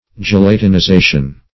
Gelatinization \Ge*lat`i*ni*za"tion\, n.